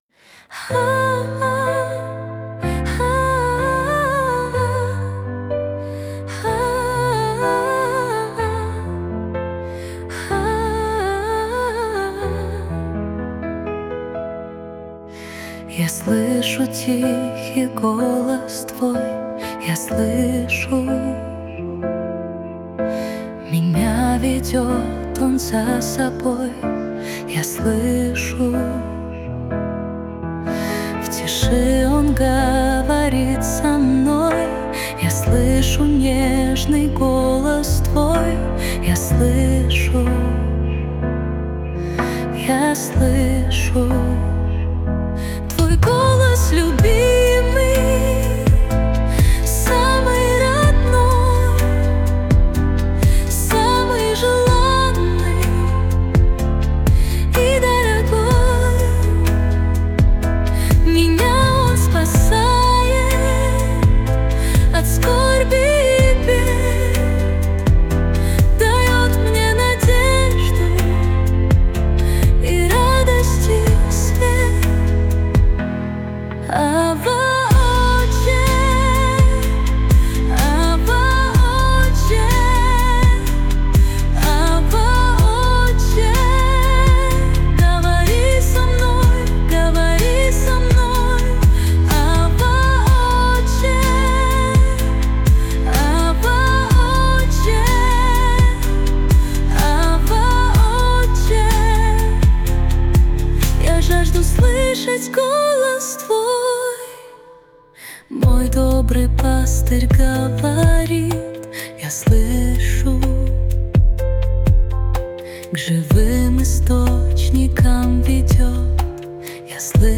388 просмотров 1327 прослушиваний 109 скачиваний BPM: 125